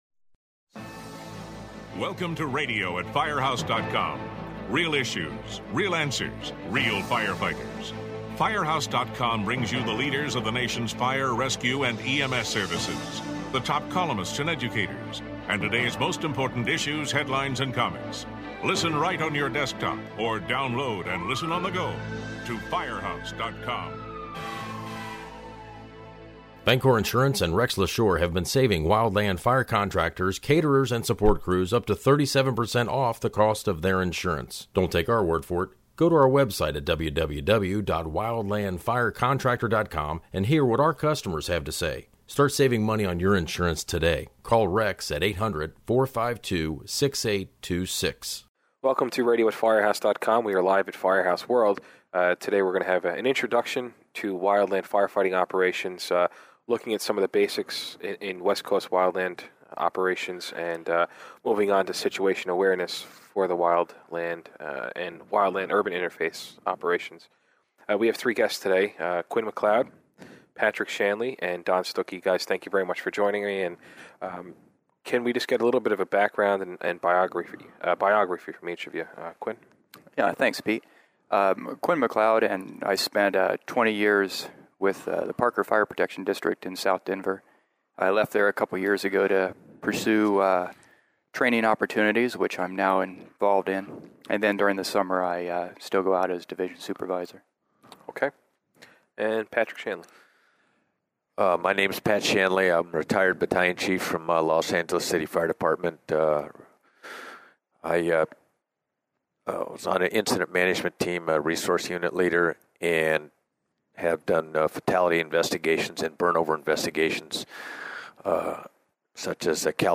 Three veteran wildland firefighters discuss a variety of topics, including situation awareness for the urban/wildland fire crews and the challenges brought on my mutual aid units...
The panel reviews mutual aid policies and talks about the variety of challenges when a large number of outside agencies are brought to the scene, including communications and the need for a good working knowledge of the surrounding geography. Note: This podcast was recorded at Firehouse World in February.